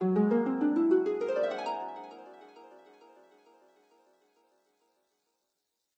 magic_harp_1.ogg